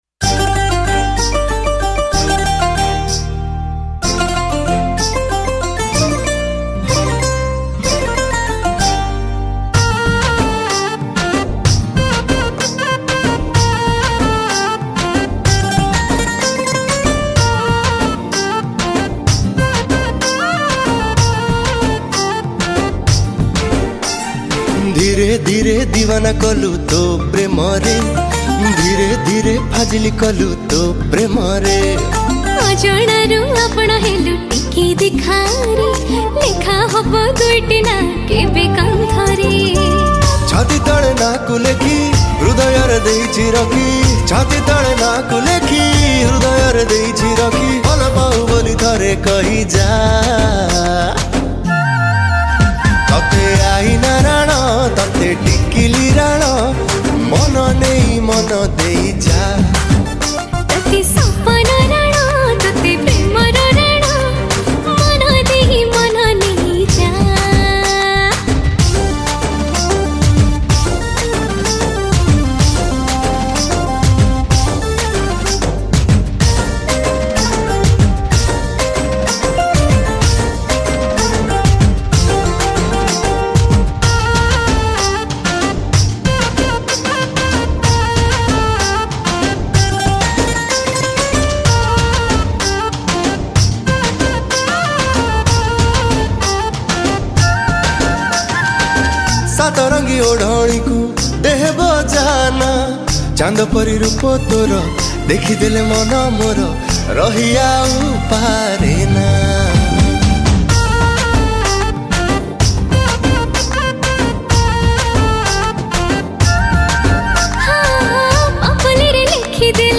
Recorded At : Pm Studio,Ctc